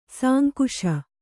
♪ sānkuśa